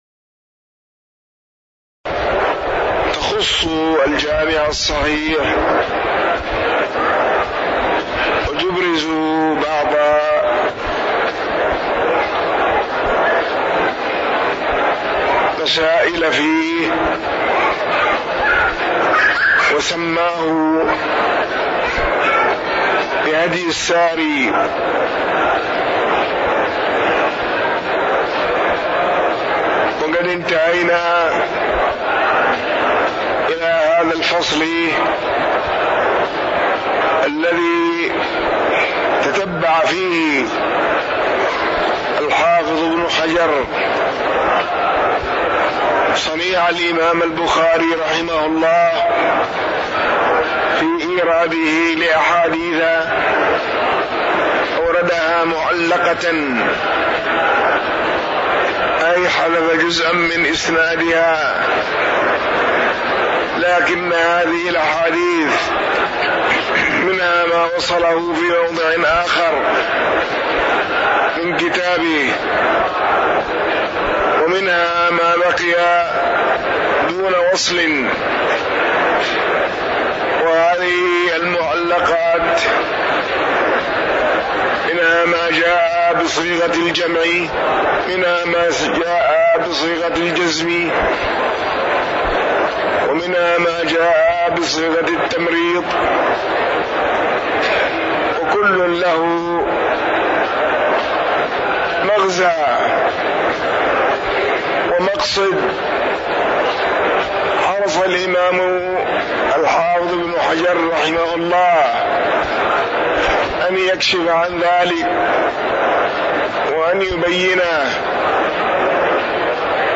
تاريخ النشر ١١ جمادى الأولى ١٤٣٩ هـ المكان: المسجد النبوي الشيخ